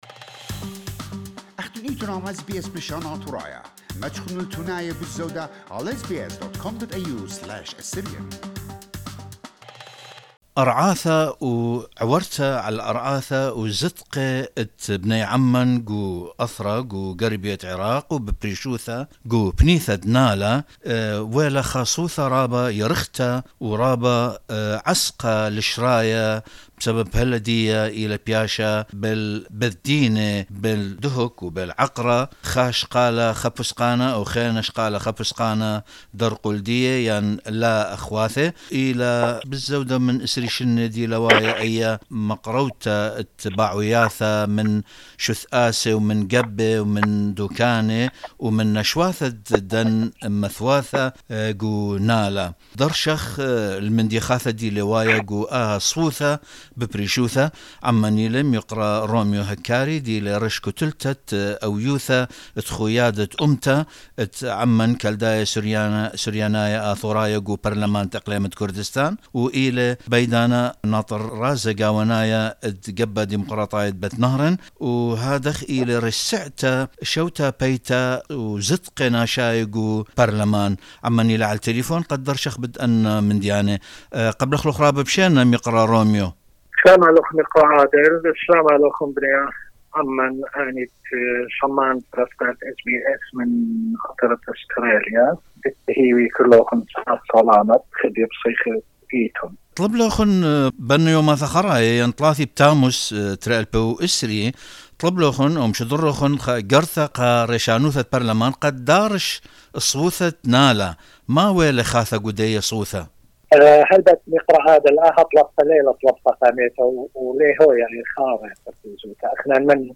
We spoke to Mr. Romeo Hakari, head of the National Unity Alliance bloc in the region’s parliament, and the Secretary-General of Bet- Nahrain Democratic Party (BNDP) and chair of the Social Affairs and Protection of Human Rights Committee in Parliament who said that we called for the presidency of the region to study the file of abuses on the lands of our people’s villages because of the sensitivity of the issue and its impact on the life and freedom of the people of those villages.